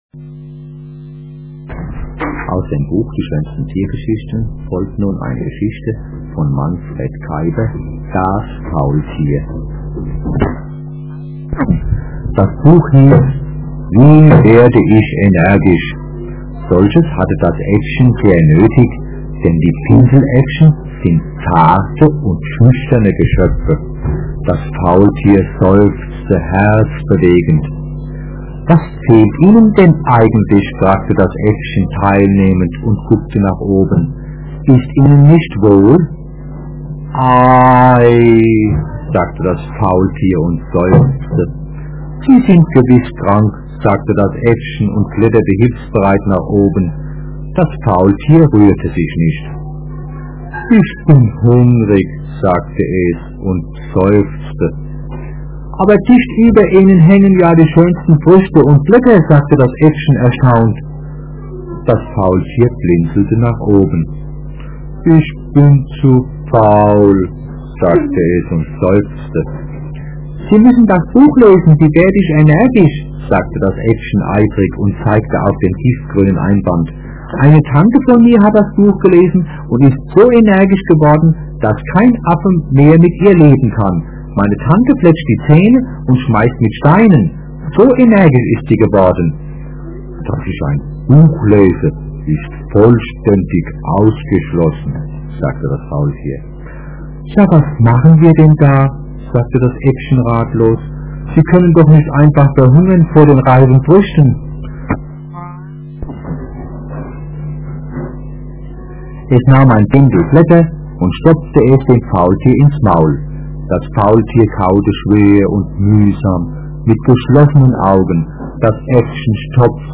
Hörspiele
- - (02:38min,MP3,ca.154KBit,sehr kleine SampleRate)